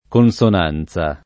[ kon S on # n Z a ]